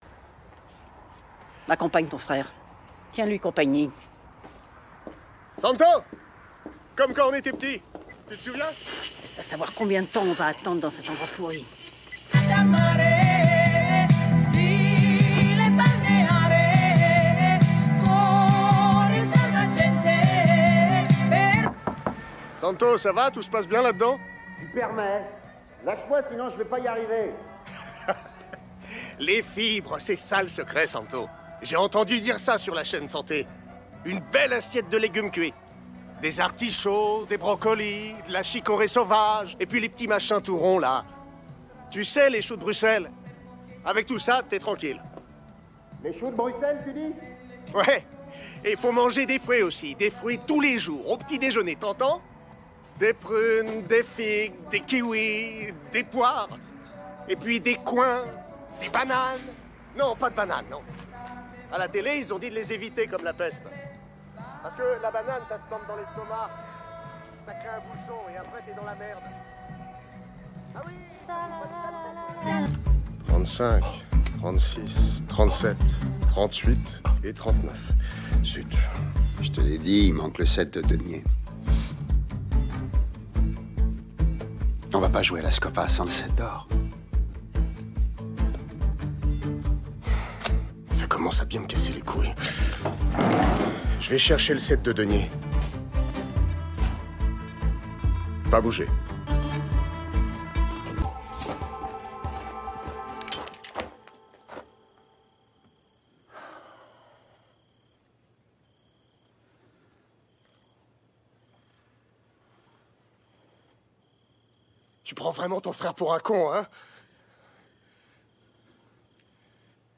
Doublage de "Bang Bang Baby" - entredeux, idiot, mafieux, amusé - 3 Extraits
Rôle d'un mafieux petite frappe bon à tout faire.
Ce personnage, à la fois idiot et mafieux, a nécessité un travail de fond pour trouver le ton juste, entre humour et menace. J’ai ainsi utilisé une hauteur de voix médium, pour donner du poids à la voix du personnage, tout en conservant une certaine légèreté pour souligner son côté maladroit.